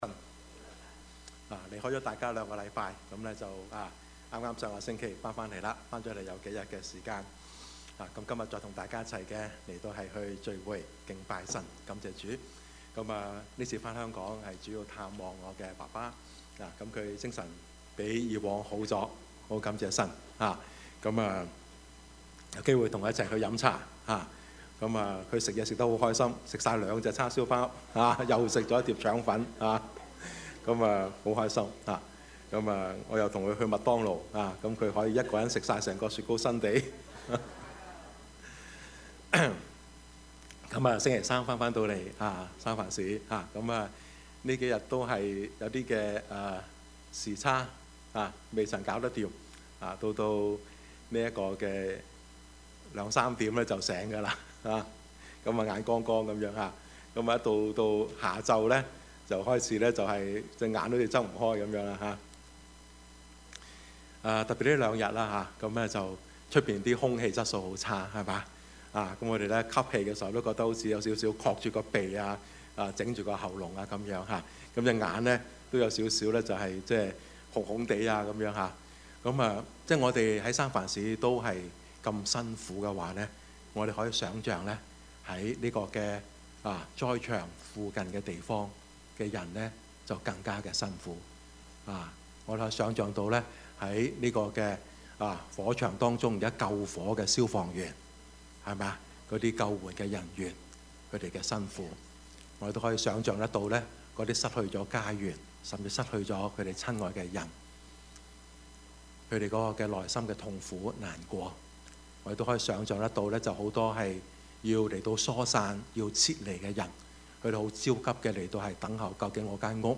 Service Type: 主日崇拜
Topics: 主日證道 « 權‧名‧錢 從敬畏到感恩，從感恩到喜樂 »